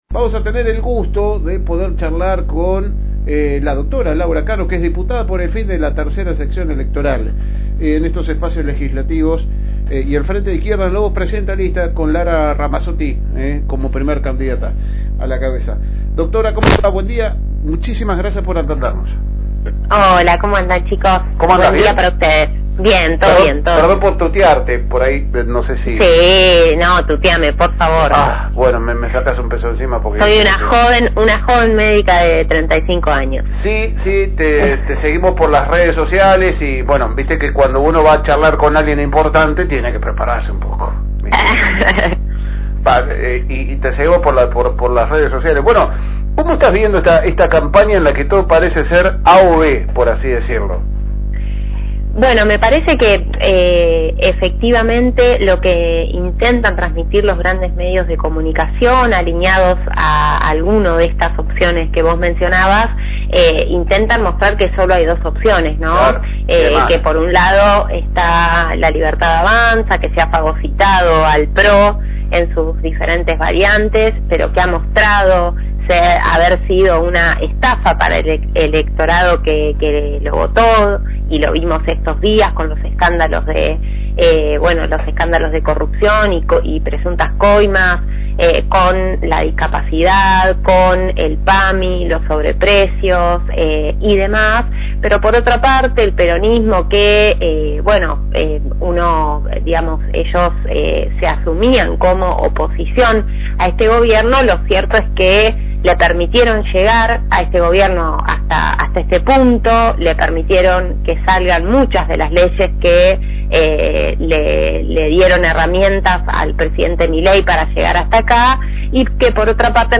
Compartimos con ustedes la entrevista con la actual diputada bonaerense del Frente de Izquierda y de los Trabajadores Laura Cano, ella es médica en la actualidad en el Hospital Cestino.